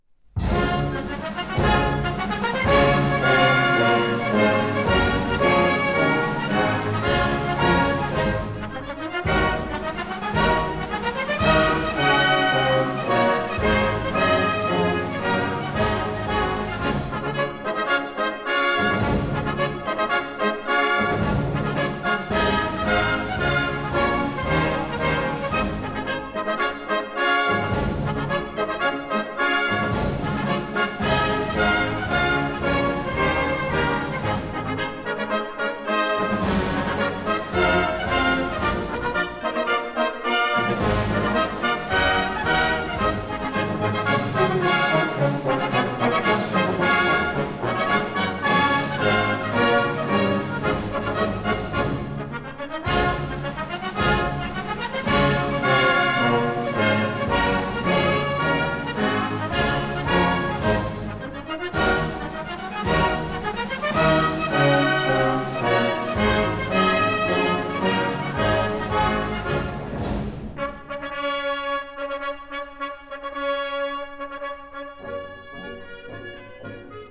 A 1959 stereo recording